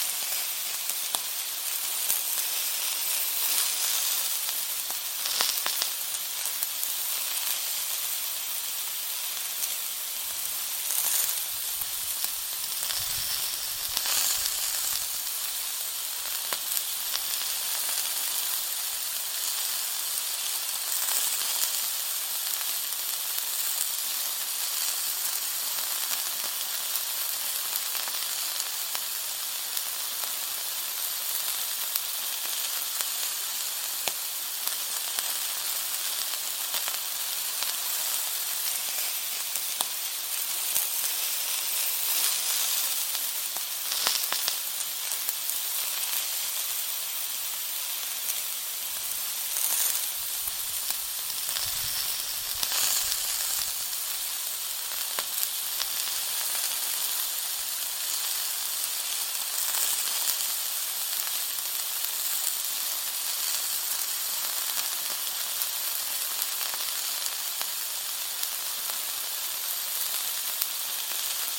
Tiếng nướng BBQ xì xèo, nướng Thịt xèo xèo…
Thể loại: Tiếng ăn uống
Description: Tiếng nướng BBQ xì xèo, âm thanh thịt cháy cạnh hấp dẫn, sôi sùng sục trên vỉ than hồng, thơm lừng mùi thịt nướng. Âm thanh xèo xèo, lép bép, tí tách chân thực như đang đứng bên bếp nướng.
tieng-nuong-bbq-xi-xeo-nuong-thit-xeo-xeo-www_tiengdong_com.mp3